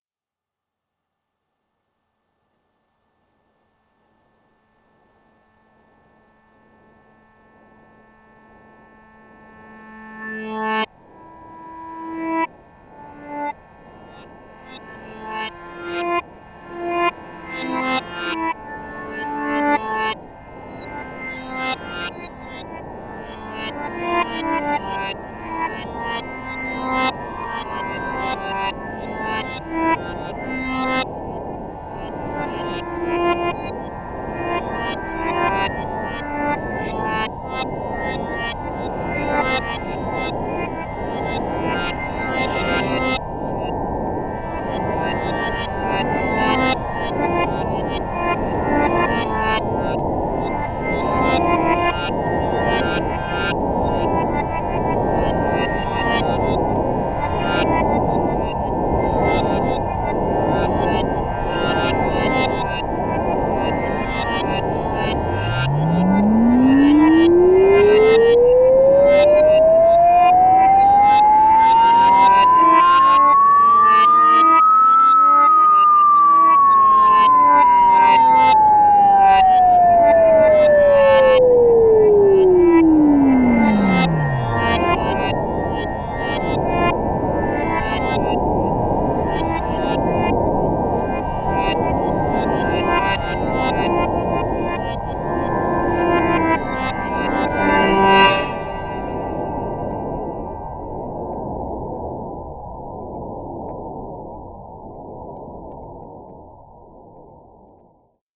AMBIENT MUSIC ; RANDOM MUSIC